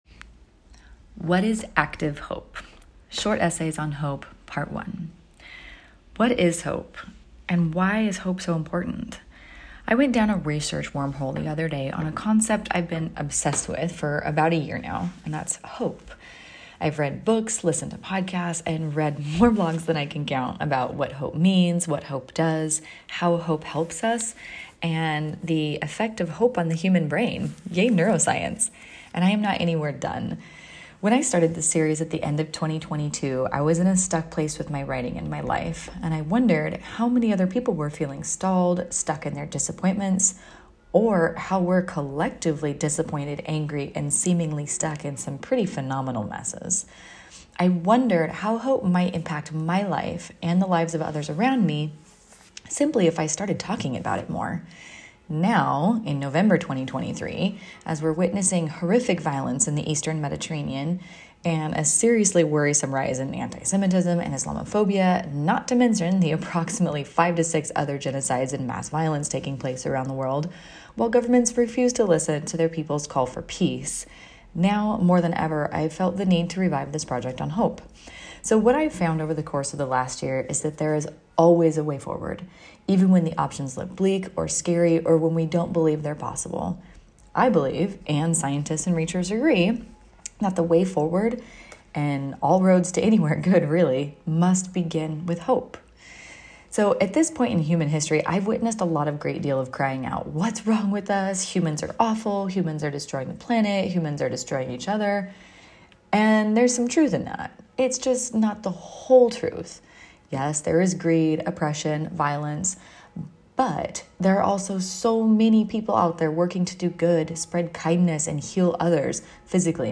LISTEN TO THIS BLOG AS AN AUDIO ESSAY!